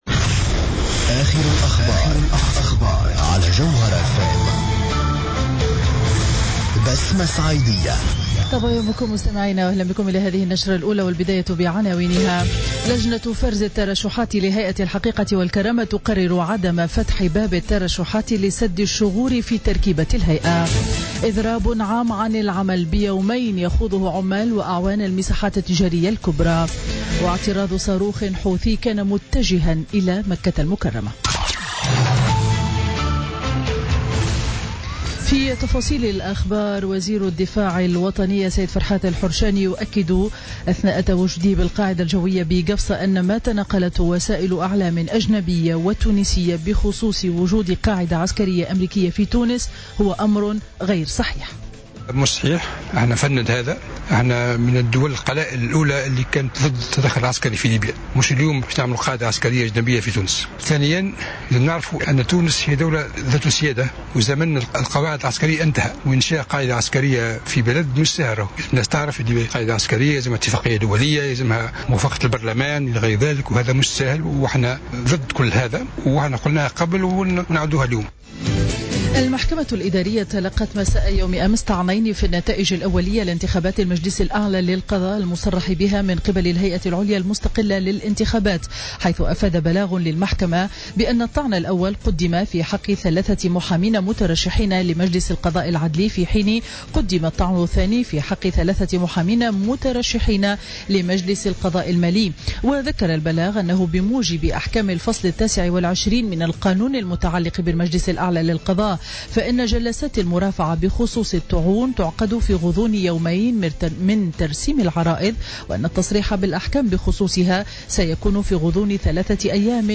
نشرة أخبار السابعة صباحا ليوم الجمعة 28 أكتوبر 2016